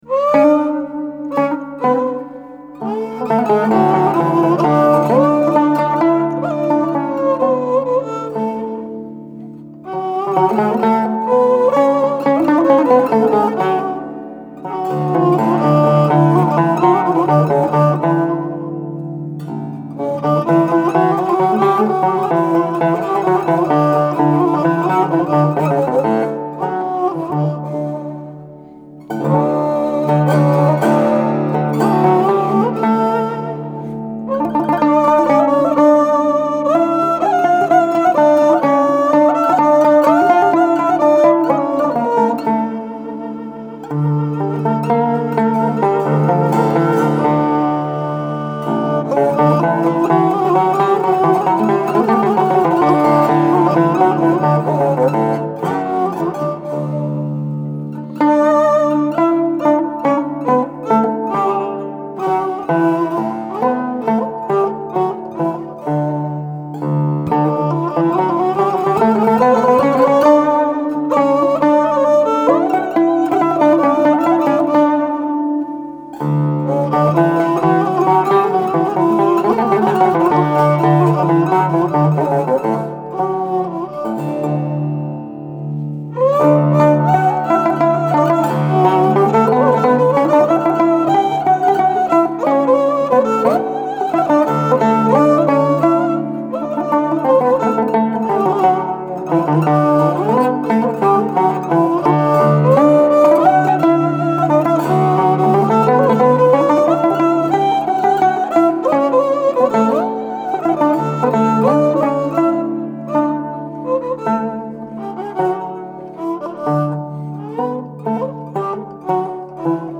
Genre: Turkish & Ottoman Classical.
Studio: Aria, Üsküdar, Istanbul